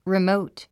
発音
rimóut　リモート